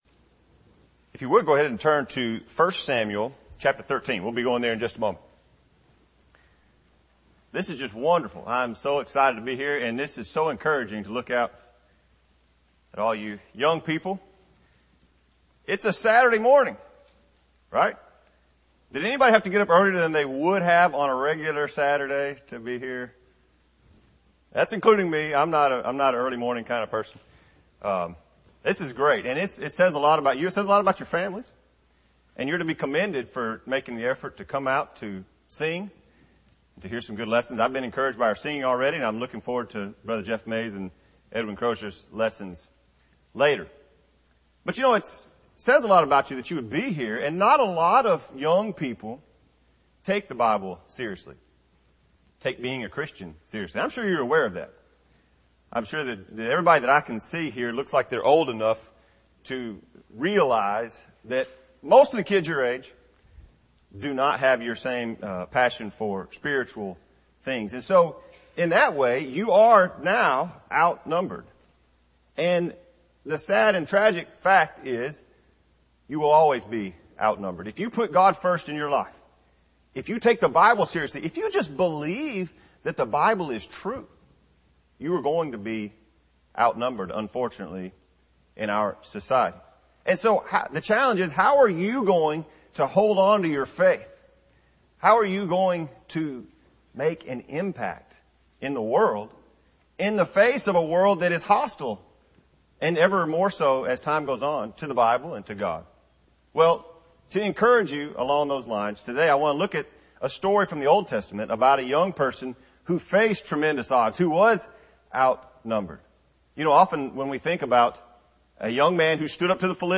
Service: Special Event Type: Sermon